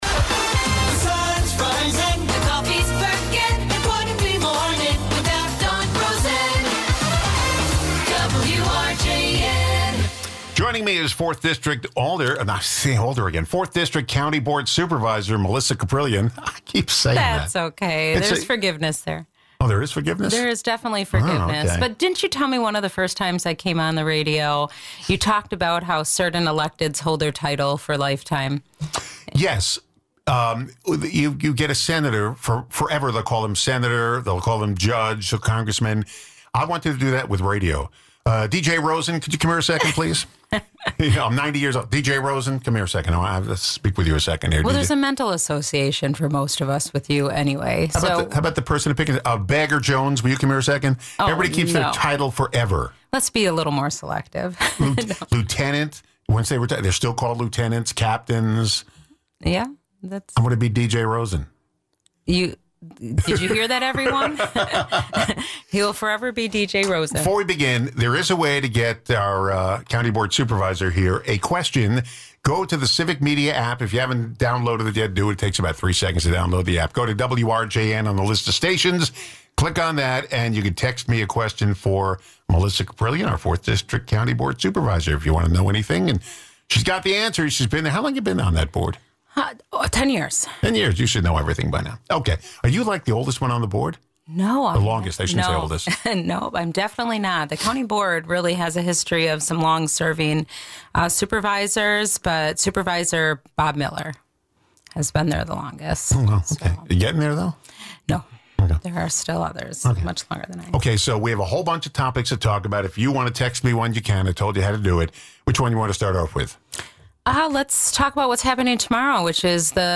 4th District Racine County Board Supervisor Melissa Kaprelian returns to update us on all the goings-on around the county.
Guests: Melissa Kaprelian